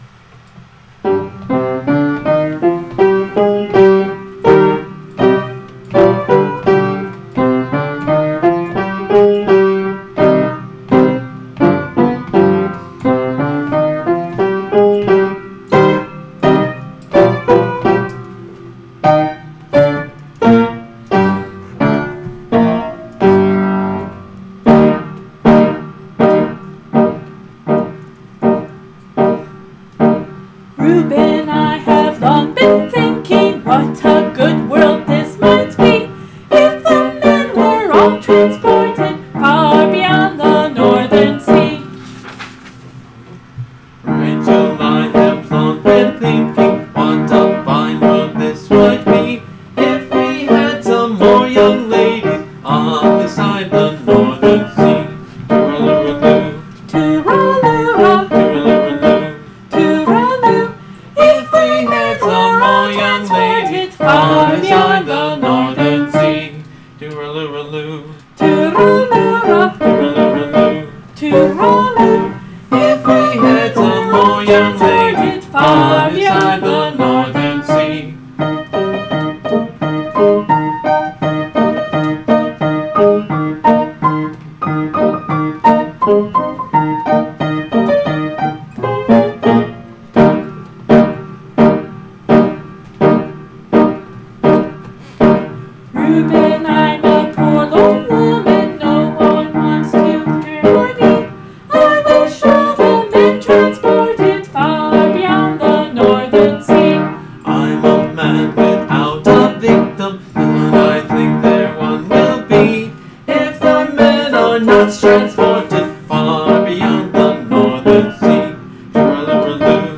Listen along with our rough “comic duet” as we sing back and forth (and my trying to play and sing – don’t listen for all the wrong notes played!).